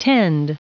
Prononciation du mot tend en anglais (fichier audio)
Prononciation du mot : tend